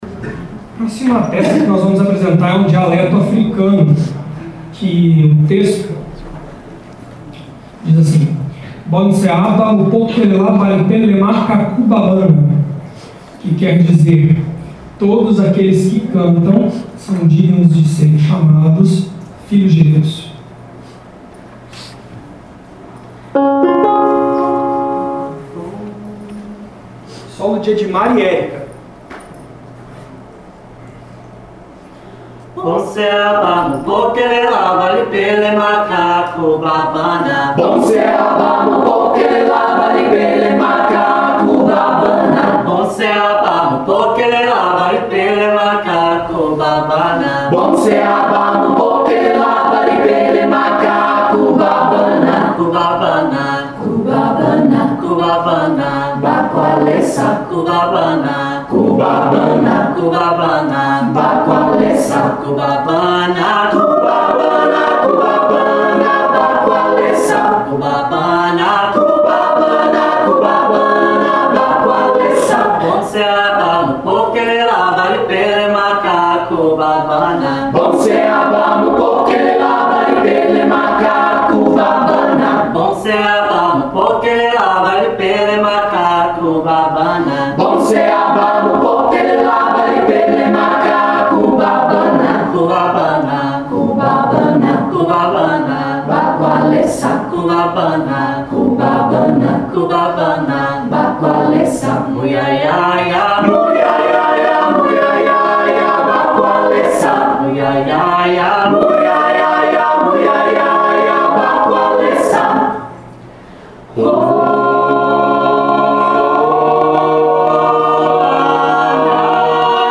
O grupo vocal “Madrigal Renascentista”, da Unifal, foi selecionado para representar o Brasil no Ameride Festival e Concurso Internacional de Corais.
Composto por 18 pessoas, entre servidores, alunos da Universidade e pessoas da comunidade alfenense
Foto: Ascom/Unifal Grupo foi convidado pela organização para participar da edição competitiva do concurso Os madrigais são tipos especiais de coral, que utilizam a técnica suave e o timbre uniforme, possibilitando a interpretação tanto de canções populares brasileiras quanto peças renascentistas.
>>Ouça uma canção africana interpretada pelo Madrigal Renascentista da Unifal A diretoria do Ameride, em “Carta de Aceite”, informou que a organização é o resultado de um enlace estratégico binacional, Brasil-Venezuela.